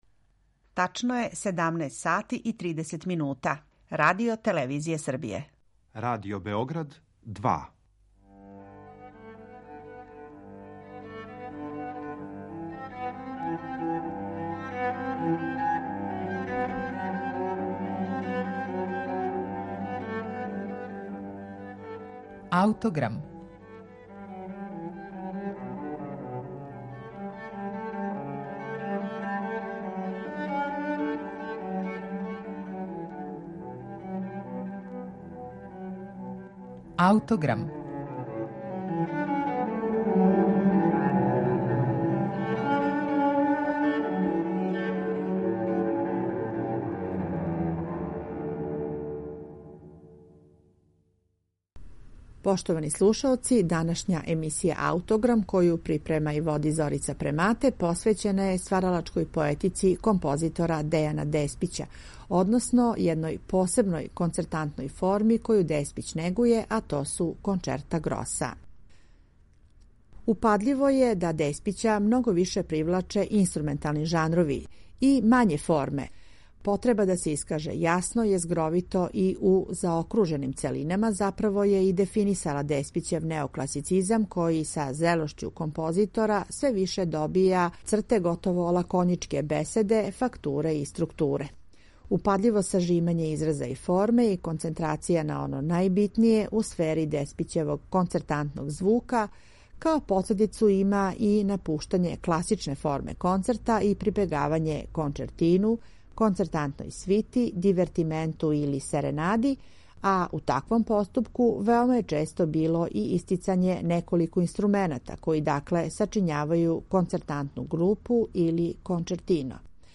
Овога пута представићемо вам кончерта гроса доајена нашег музичког стваралаштва, Академика Дејана Деспића. Његов неокласични стил, коме је остао веран током седам деценија дуге уметничке каријере, биће сагледан као савремен и креативан допринос овој барокној концертантној форми. Емитоваћемо Кончертино за кларинет и фагот, Кончерто гросо за флауту, обоу, кларинет и фагот, Дивертименто кончертанте за кларинет, хорну, трубу и гудаче и Кончертино за две флауте и камерни оркестар.